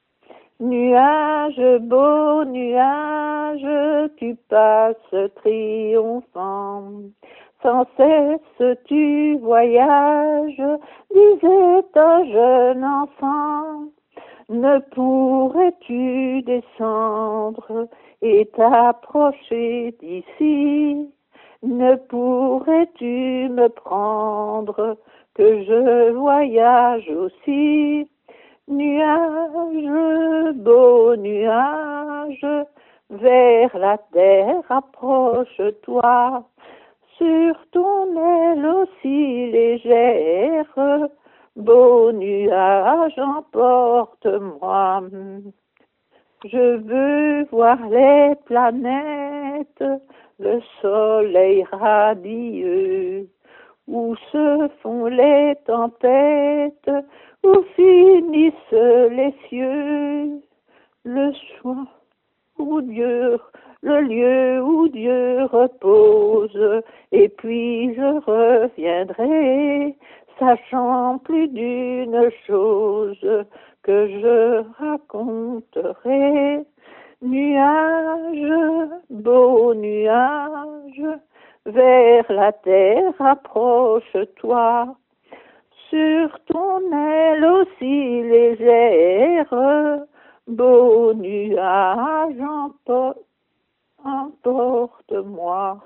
Type : chanson narrative ou de divertissement | Date : 2020